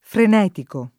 vai all'elenco alfabetico delle voci ingrandisci il carattere 100% rimpicciolisci il carattere stampa invia tramite posta elettronica codividi su Facebook freneticare v.; frenetico [ fren $ tiko ], -chi — cfr. farneticare